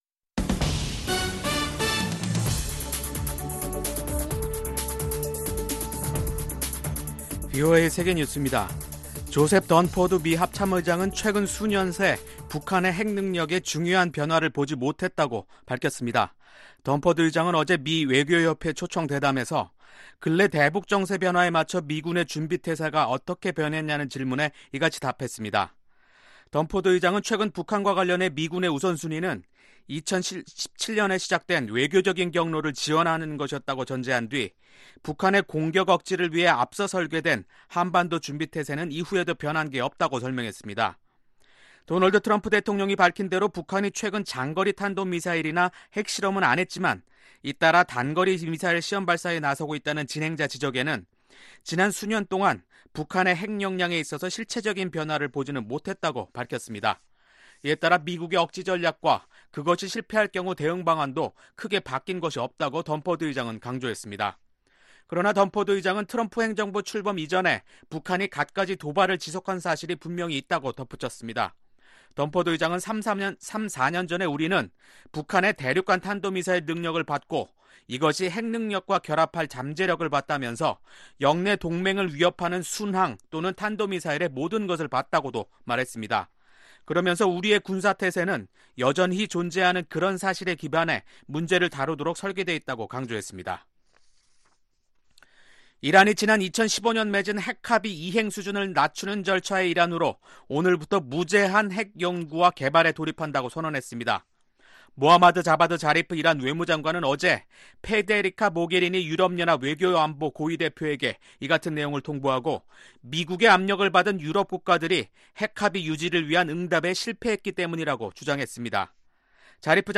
VOA 한국어 간판 뉴스 프로그램 '뉴스 투데이', 2019년 9월 6일 2부 방송입니다. 유엔 바지선을 이용해 북한이 석탄운송을 계속하고 있다고 밝혔습니다. 태풍 링링이 한반도를 위협하고 있는 가운데, 북한의 재난대비 상태는 매우 취약한 것으로 나타났습니다.